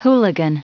Prononciation du mot hooligan en anglais (fichier audio)
hooligan.wav